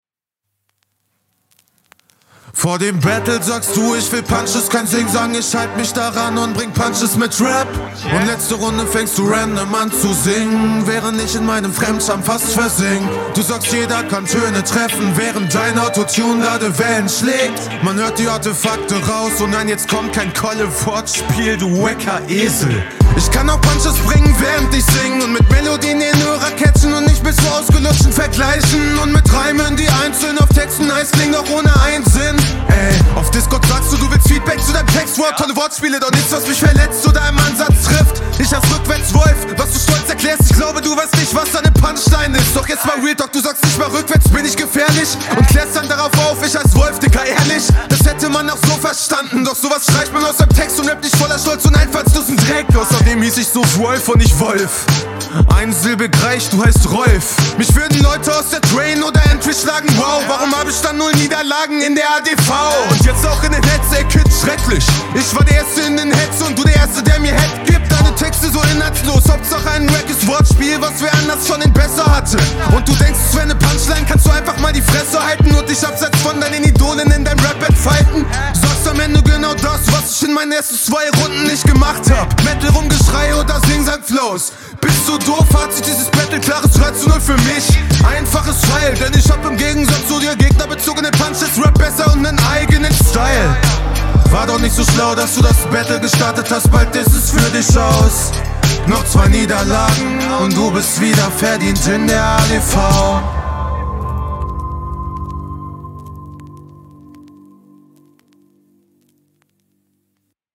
find ganz cool wie du hier rapst. könntest noch stärker delivern aber finds angenehmer zu …
Stimmlich isses wieder sehr laid back, is natürlich n vertretbares Stilmittel, ich glaube aber, du …